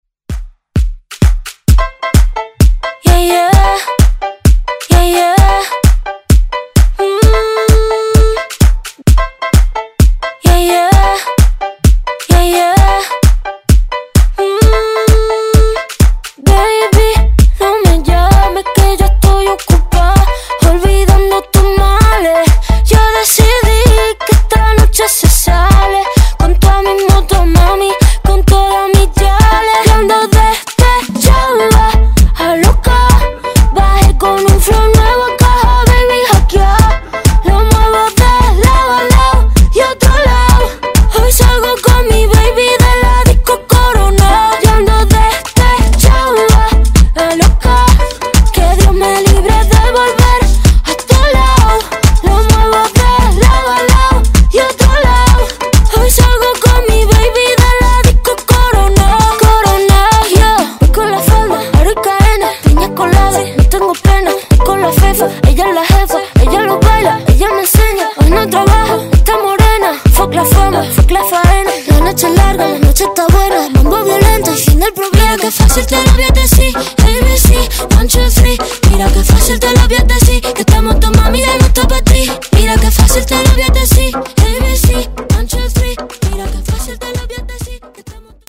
Genre: AFROBEAT
Clean BPM: 104 Time